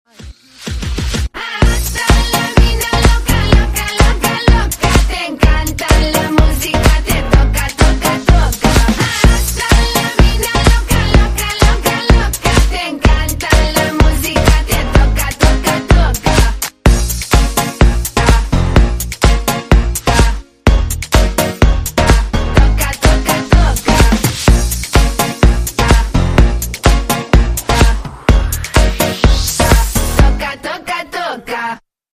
поп
веселые